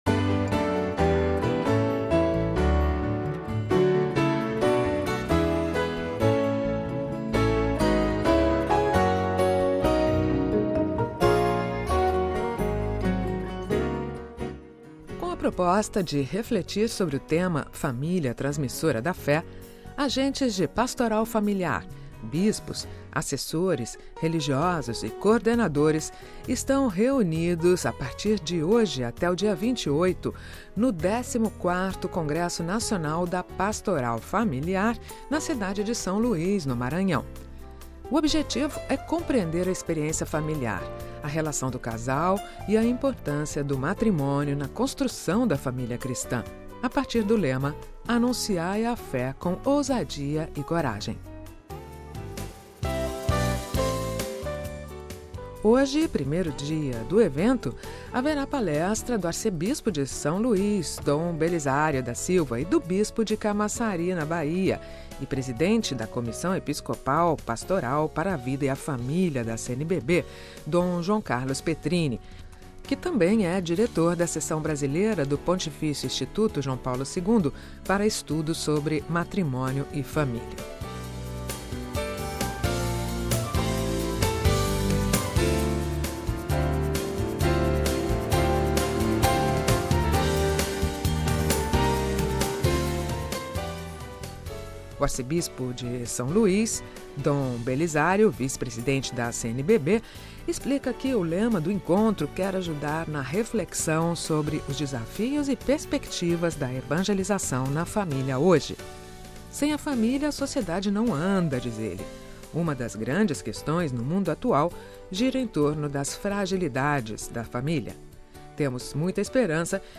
Confira a mensagem de Dom Belisário para o Congresso: RealAudio